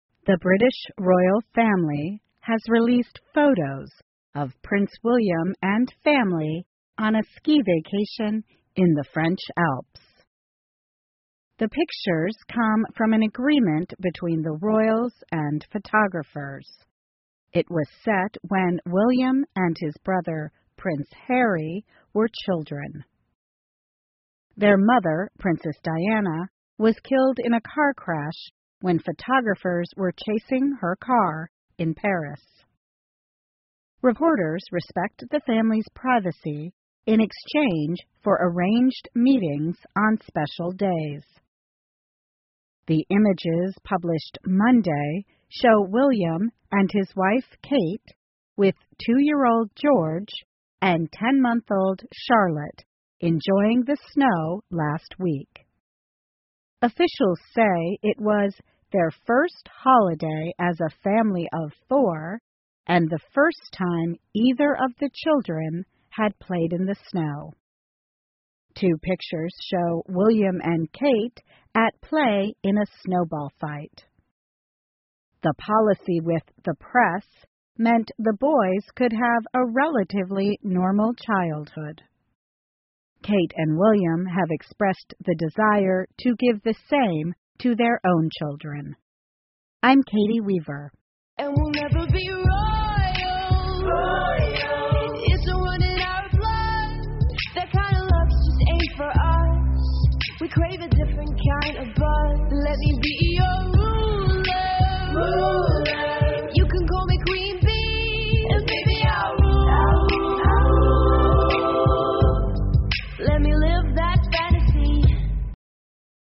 VOA慢速英语2016 威廉王子一家四口滑雪度假 听力文件下载—在线英语听力室